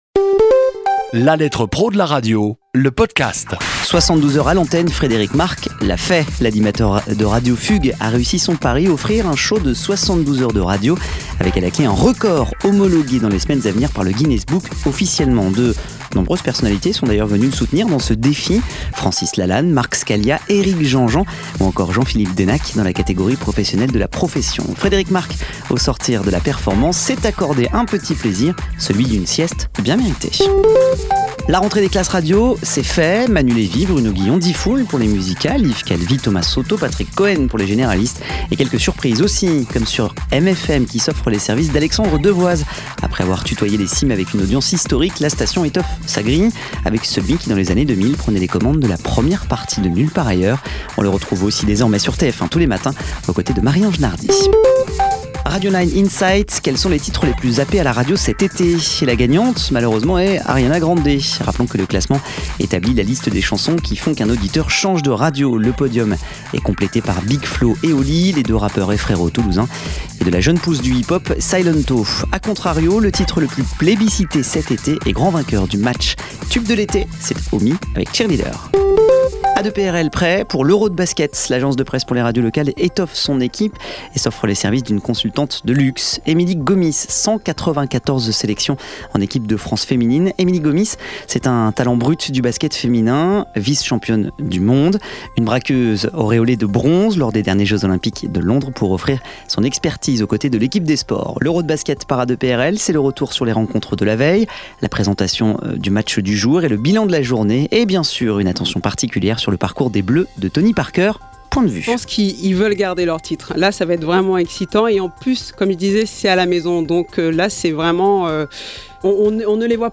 Un condensé de l'actualité de la semaine traitée ici ou dans le magazine. Cette capsule propose également des interviews exclusives de professionnels de la radio.